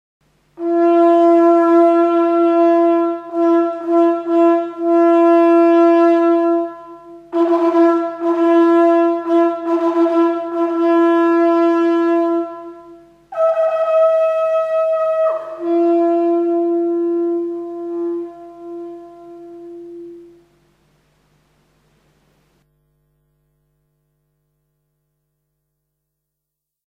Sound category Instrument Sound item Olifant